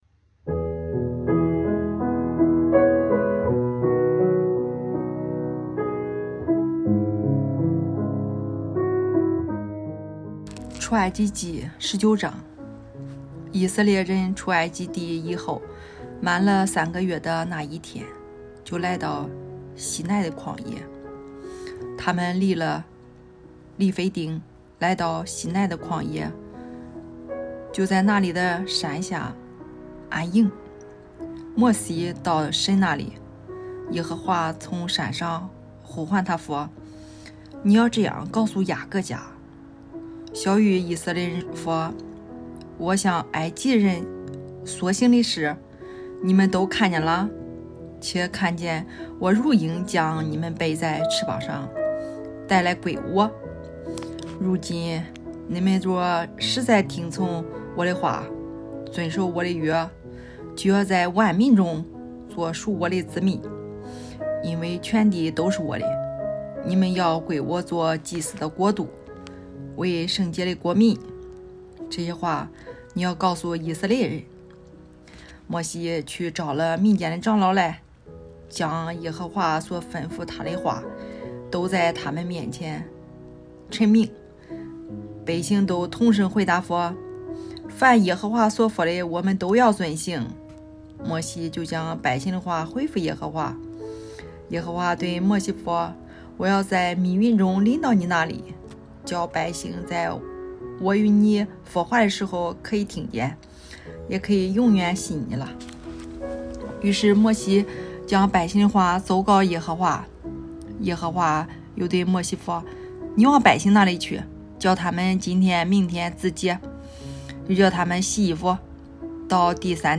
读经马拉松 | 出埃及记19章(菏泽话)
语言：菏泽话